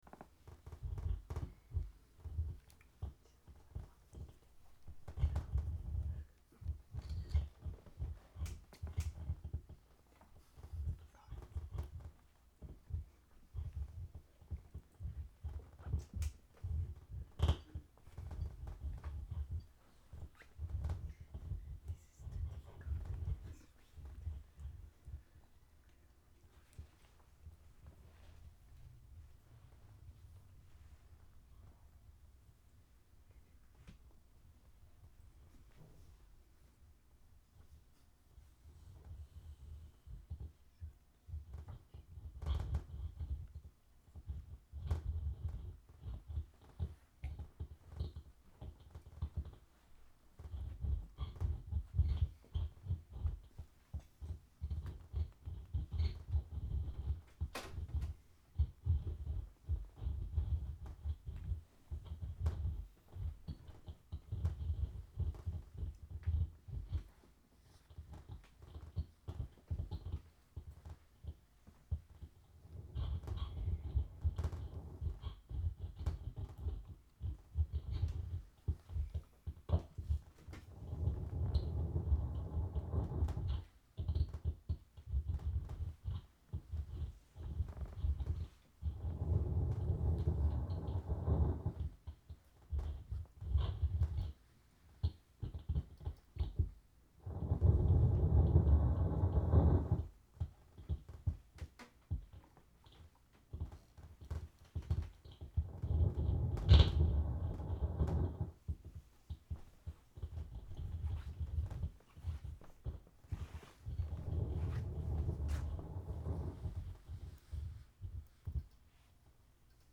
Three pieces recorded during a session held last December.